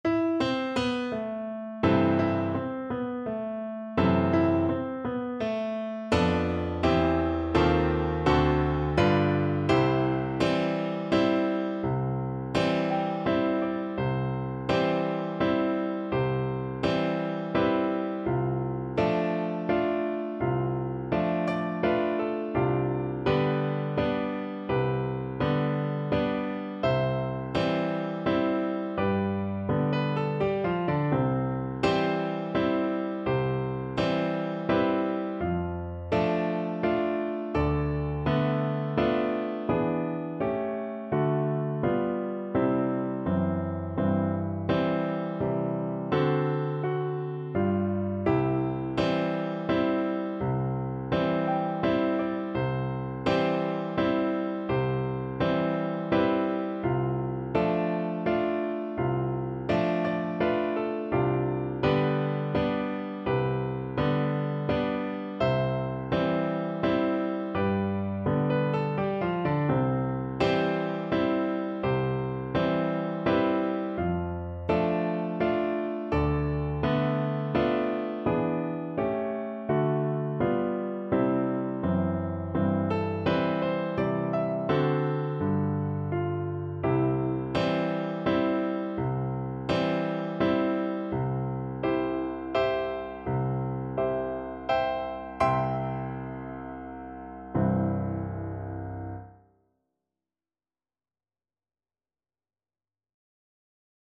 Play (or use space bar on your keyboard) Pause Music Playalong - Piano Accompaniment Playalong Band Accompaniment not yet available reset tempo print settings full screen
3/4 (View more 3/4 Music)
= 84 Andante non troppe e molto maestoso
C major (Sounding Pitch) (View more C major Music for Percussion )